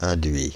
Prononciation
Prononciation Paris: IPA: [ɛ̃.dɥi] France (Île-de-France): IPA: /ɛ̃.dɥi/ Le mot recherché trouvé avec ces langues de source: français Les traductions n’ont pas été trouvées pour la langue de destination choisie.